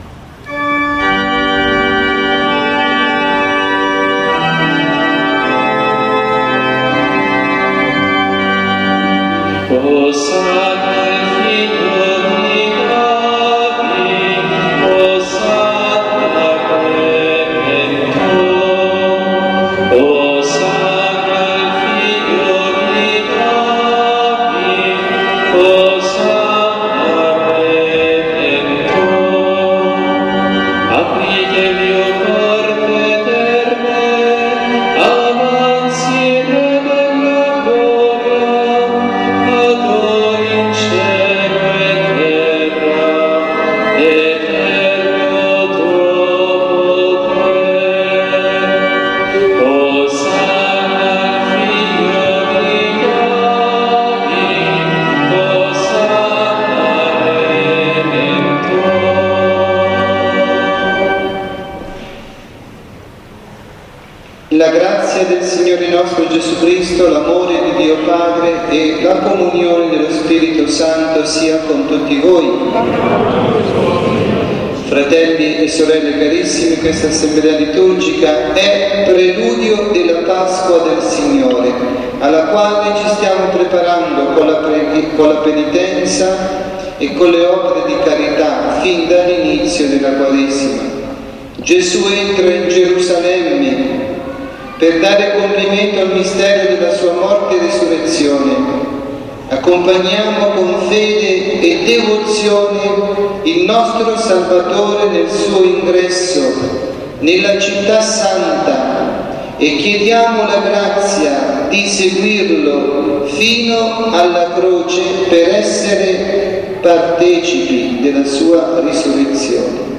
Omelia